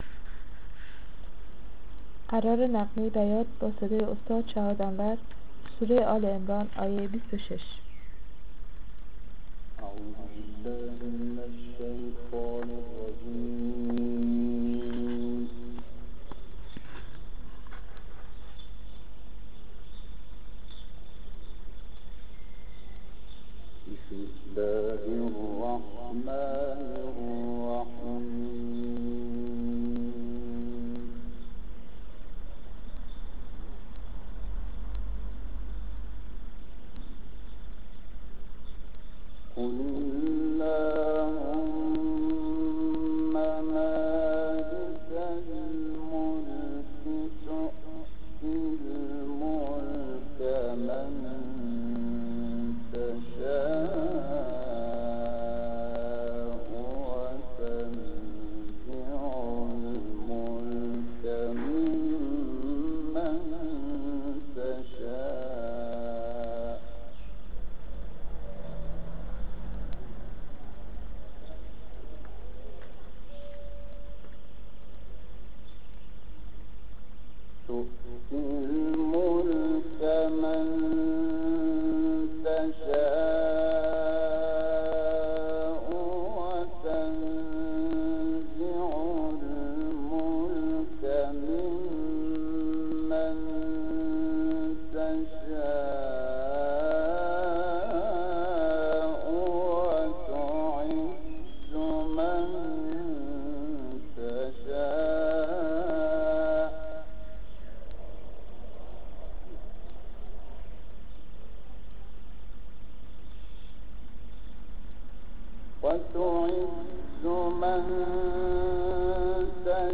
قرار بیات شحات - سایت قرآن کلام نورانی - آل عمران 26.mp3